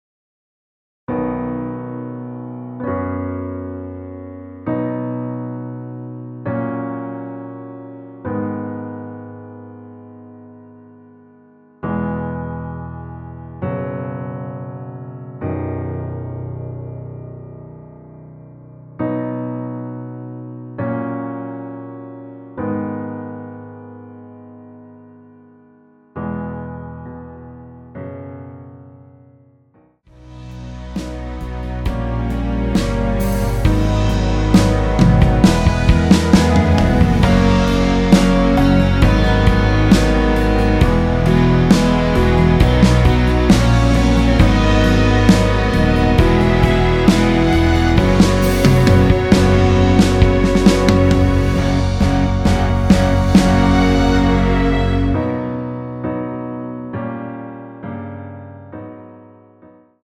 전주없이 시작하는 곡이라 1마디 전주 만들어 놓았습니다.(미리듣기 참조)
원키에서(-2)내린 MR입니다.
앞부분30초, 뒷부분30초씩 편집해서 올려 드리고 있습니다.